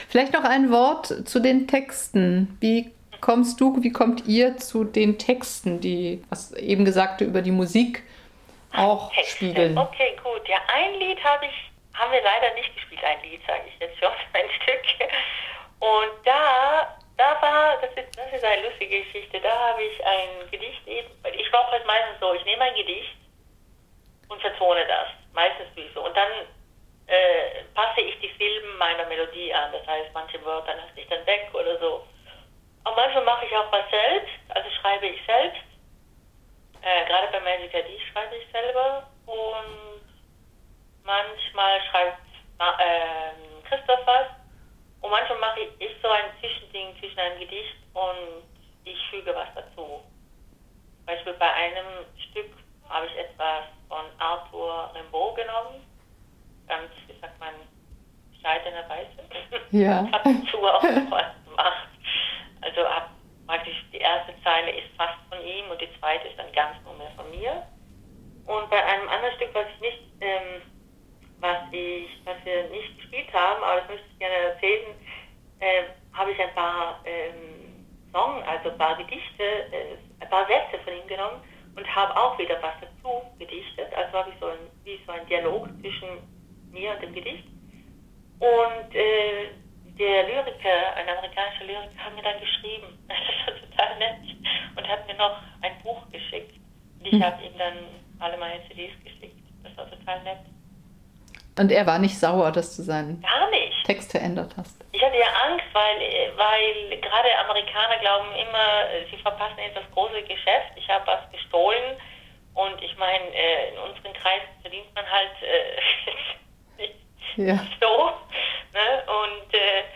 Auf Wolke 7 war ich allerdings, als ich in der diesjährigen Ausgabe von klub katarakt unverhofft in seine Klangfänge geriet – Klänge, die einen mit subtiler Sanftheit  nach innen ziehen, um einen alsbald wieder frech auszuspucken, kumpelig anzustupsen und wehmütig-schräg zu stimmen.